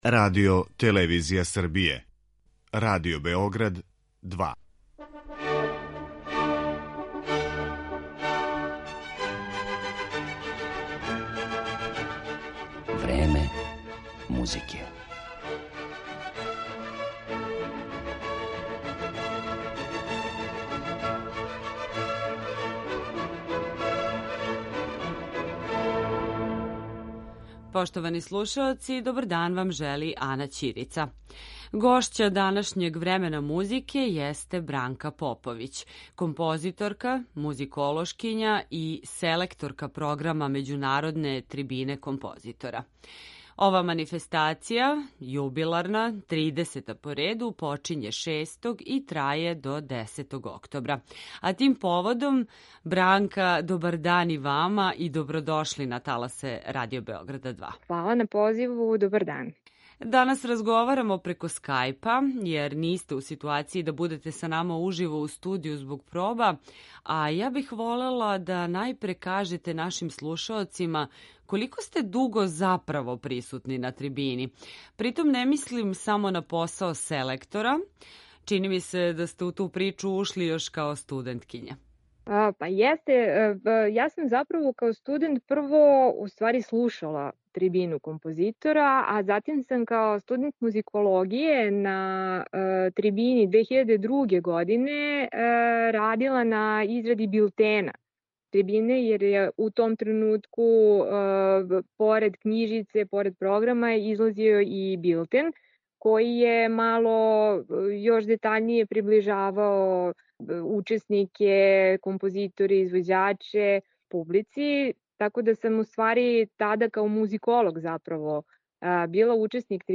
Аудио подкаст